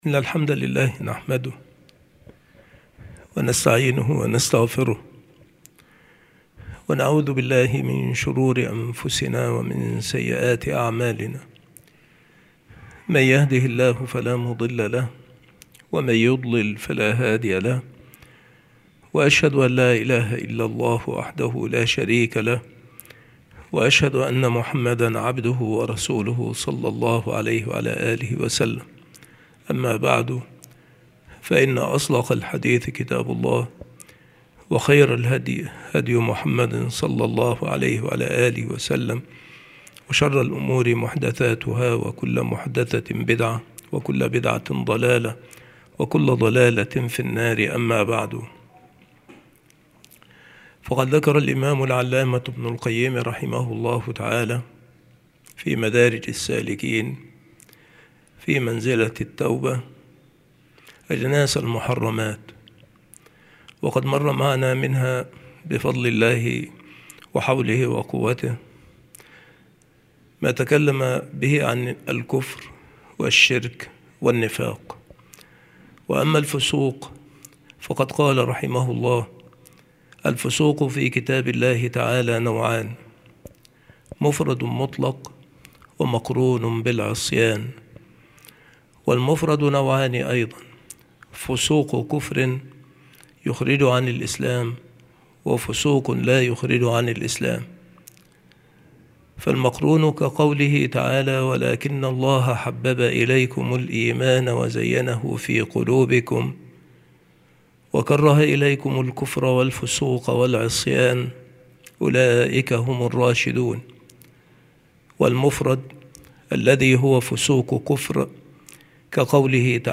مكان إلقاء هذه المحاضرة المكتبة - سبك الأحد - أشمون - محافظة المنوفية - مصر عناصر المحاضرة : الفسوق نوعان.